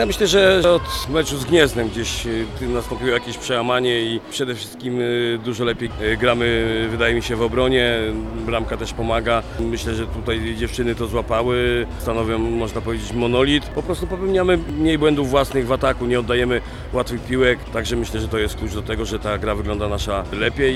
Bramka też pomaga – mówi w rozmowie z Radiem Lublin.